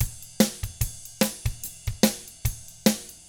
146ROCK T3-L.wav